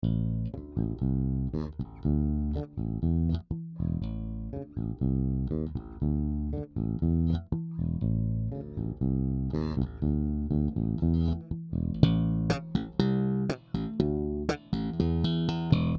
NormEQ
Edit: teď jak to slyšim bez těch kytar a bicích tak je sakra znát jak moc musim zamakat na přesnosti, úděsně to kulhá ...